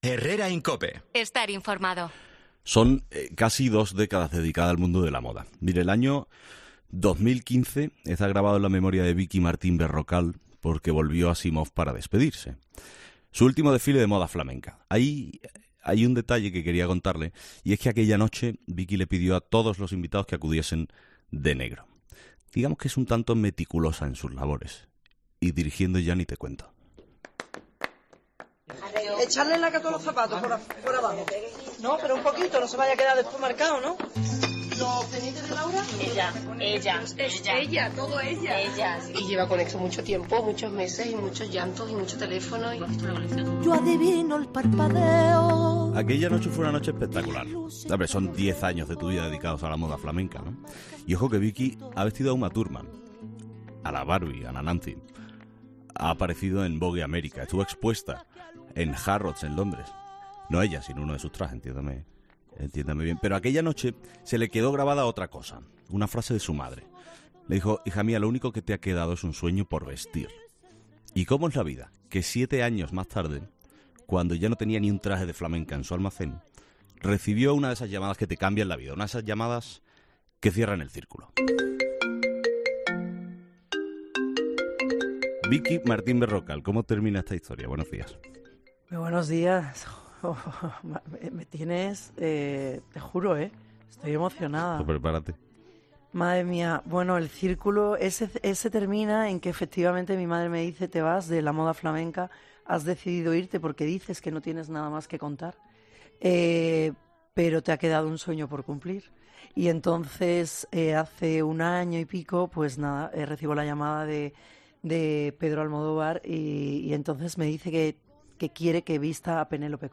Vicky Martín Berrocal ha venido a los estudios de 'Herrera en COPE' para presentar su nuevo libro: 'La felicidad ni tiene talla ni tiene edad'.